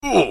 Poop Burp Sound